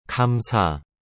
• 감사 gamsa: O “g” soa suave, como se fosse uma mistura de “g” e “k”. Já o “sa” é bem claro, como em “sala”.